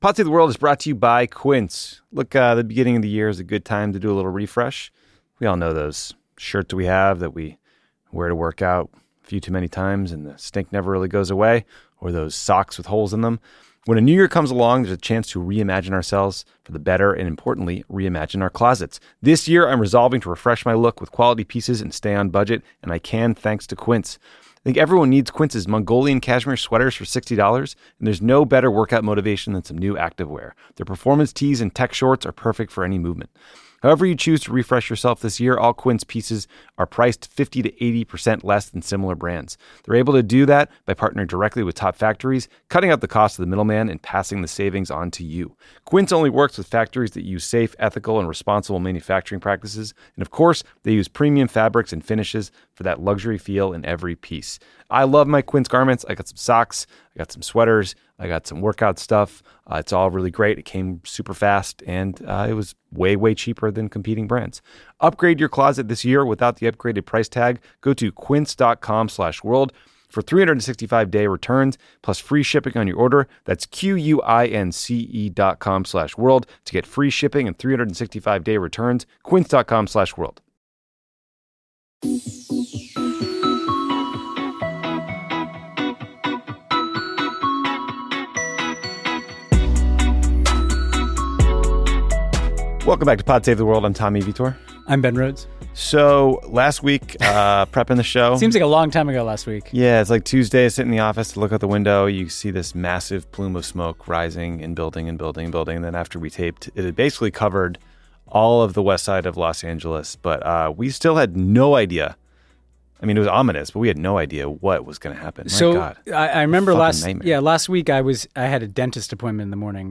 Tommy and Ben discuss Pete Hegseth’s confirmation hearing for Secretary of Defense, President Biden’s final foreign policy speech, and the many global challenges Donald Trump will be inheriting on his first day in office. They also talk about the potential for a last minute ceasefire deal between Israel and Hamas, Lebanon’s new president, far-right parties and candidates that are ascendant in Croatia, Austria, and Germany, Paul Manafort’s international comeback attempt, and the politics of naming aircraft carriers. Then, Ben speaks with Ian Bremmer, founder and president of the Eurasia Group, about the top global risks of 2025.